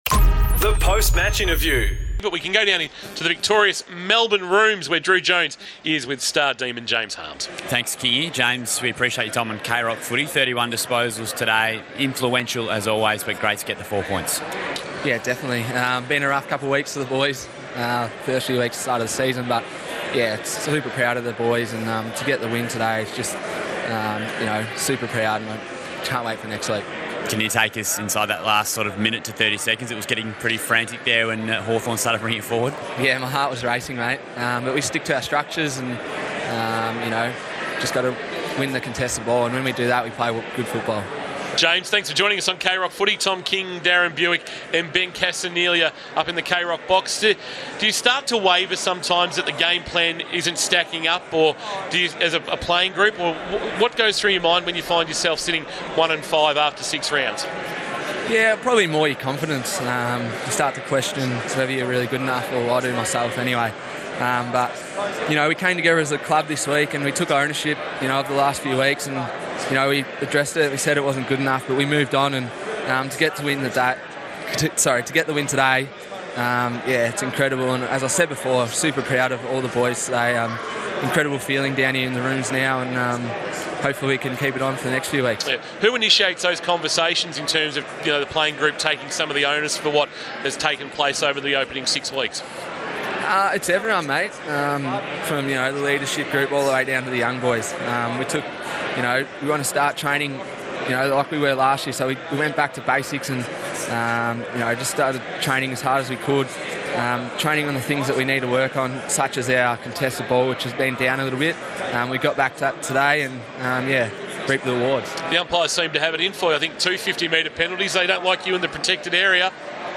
POST-MATCH: JAMES HARMES - Melbourne v Hawthorn